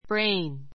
brain A1 bréin ブ レ イン 名詞 ❶ 脳 , 脳みそ ❷ ふつう brains で （優 すぐ れた） 頭脳 , 知能 Use your brain(s).